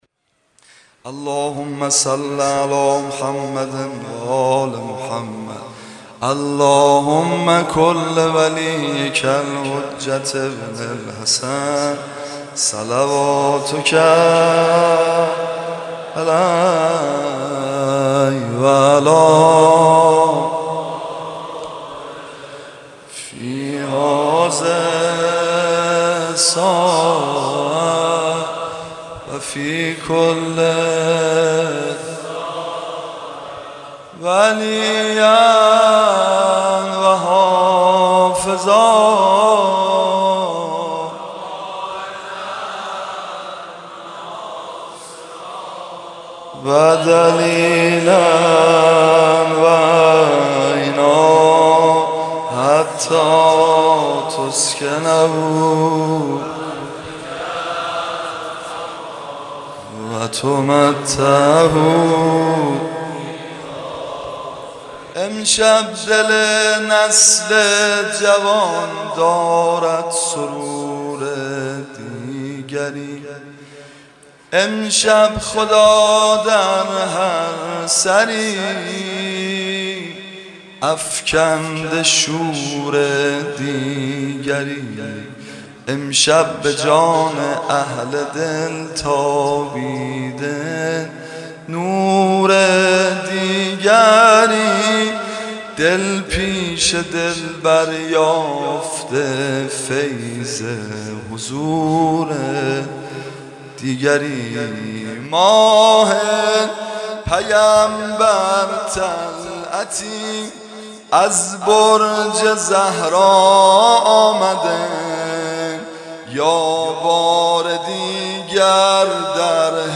شب میلاد حضرت علی اکبر (ع)/ هیأت شهدای گمنام
در حسینیه شهدای قهرود برگزار گردید.